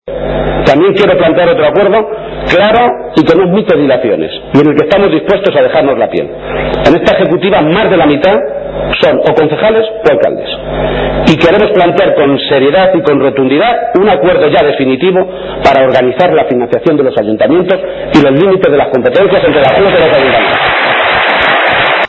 Audio Page-clausura Congreso PSOE C-LM-3